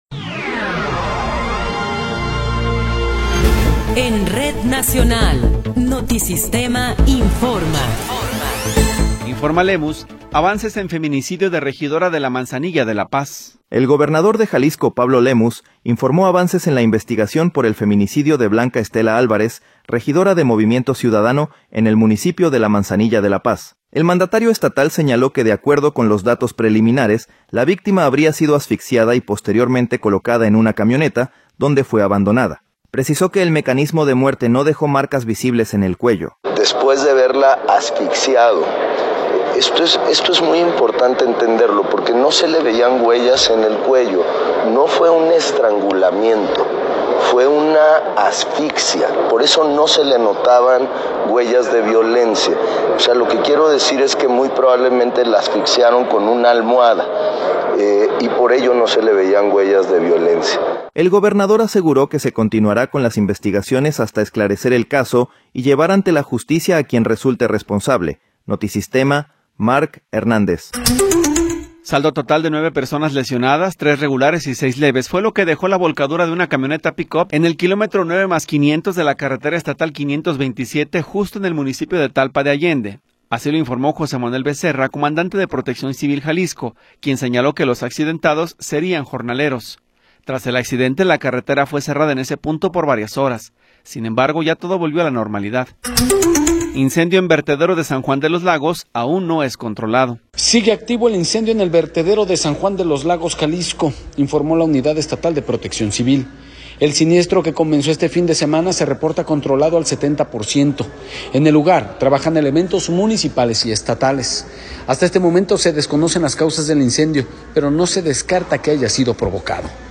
Noticiero 16 hrs. – 16 de Febrero de 2026